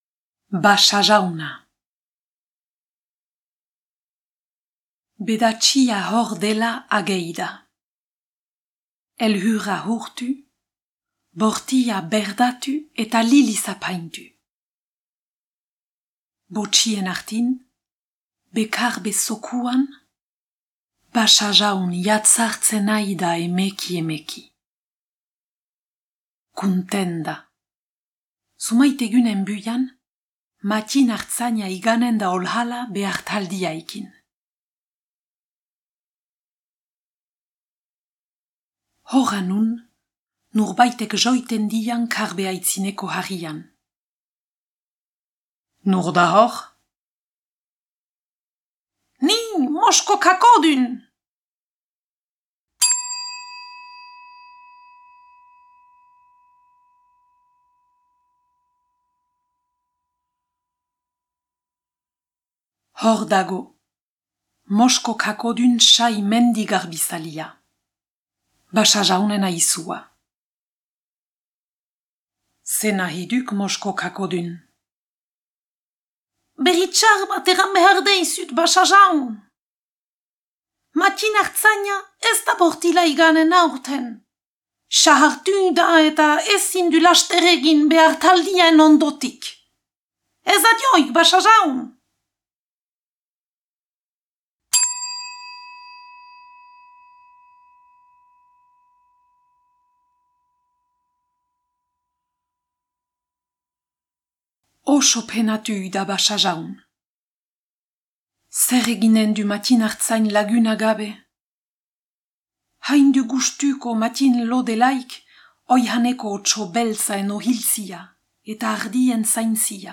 Basajauna - zubereraz